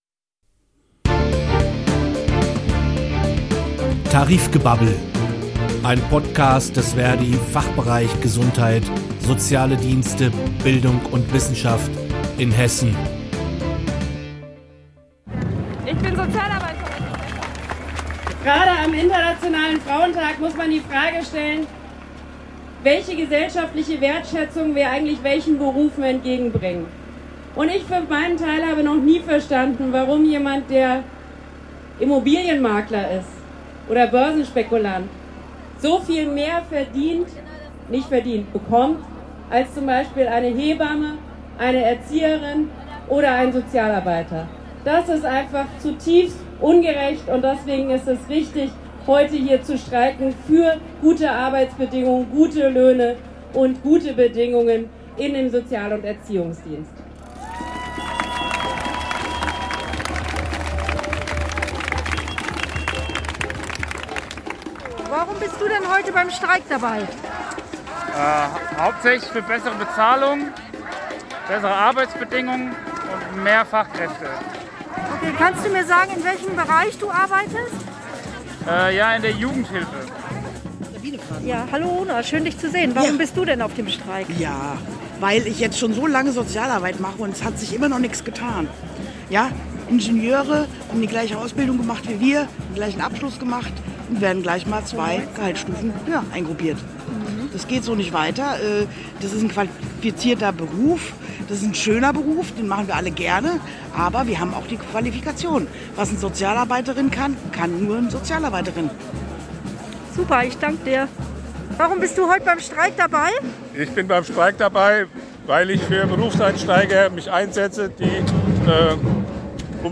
In dieser Folge wollen wir besonderes Augenmerk auf die Soziale Arbeit richten. Deshalb freuen wir uns dass 4 Kolleg*innen aus der Region Frankfurt zum Gespräch gekommen sind. Sie berichten über Ihre Forderungen und wie es ist gerade jetzt in den Streik zu gehen. Leider hat uns die Technik verlassen, sodass die Aufnahme etwas blechern klingt.